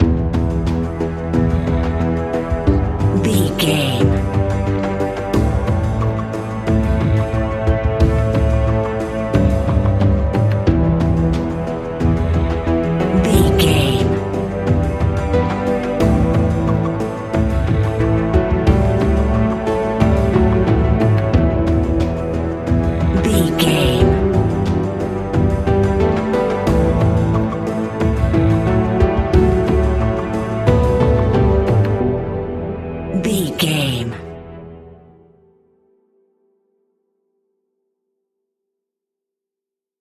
Aeolian/Minor
ominous
dark
haunting
eerie
percussion
synthesizer
instrumentals
horror music